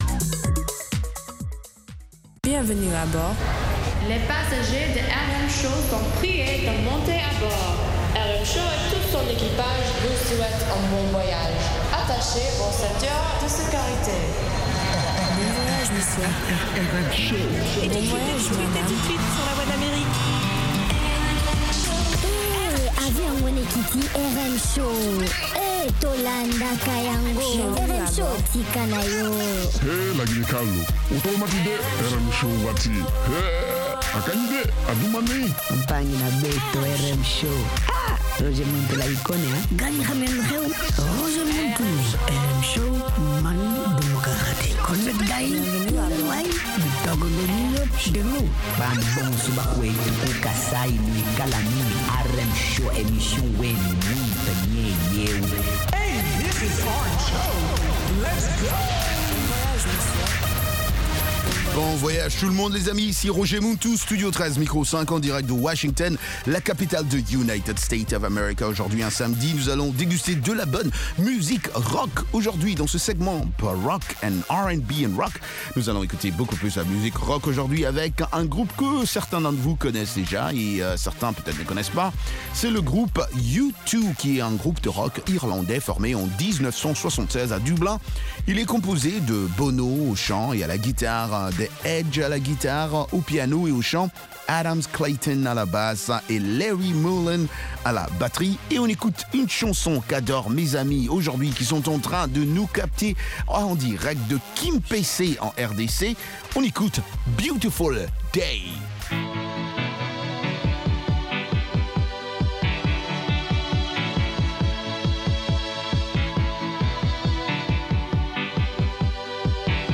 RM Show - R&B et Rock